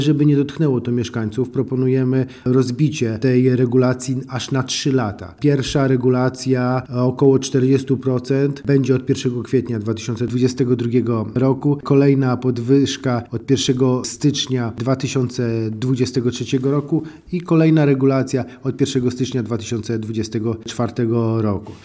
O szczegółach mówi- Wiceprezydent, Jerzy Zawodnik: